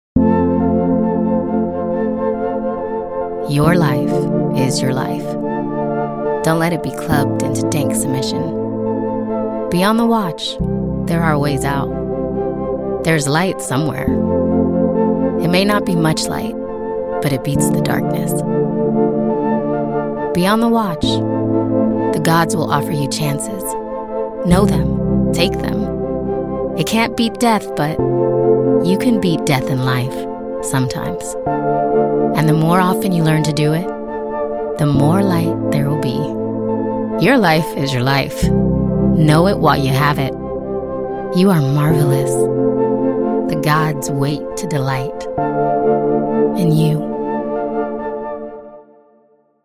Voice Type:  Expressive, rich, conversational, with a touch of rasp.